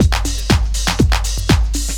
Milky Beat 2_121.wav